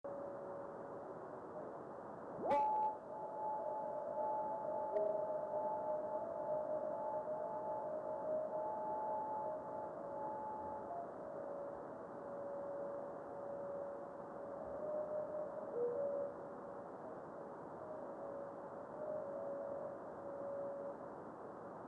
video and stereo sound:
Good head echo.